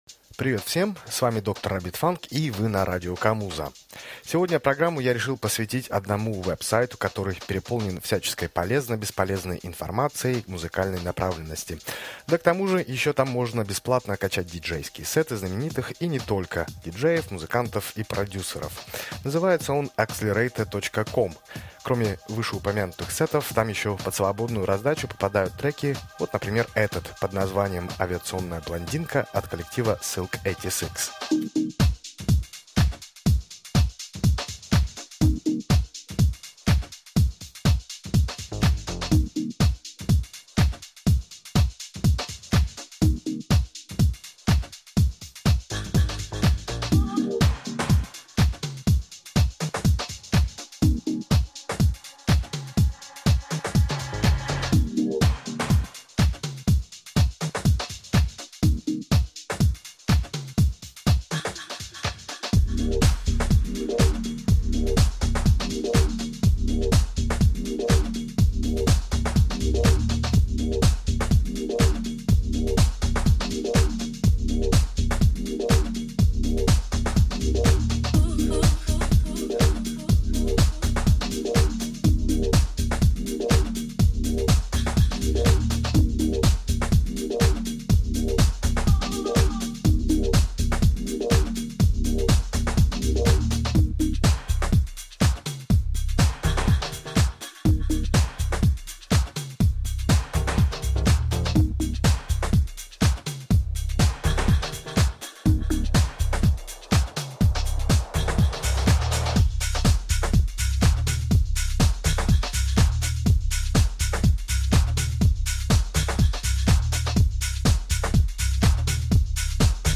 лучшие танцевальные треки
электронно-танцевальные треки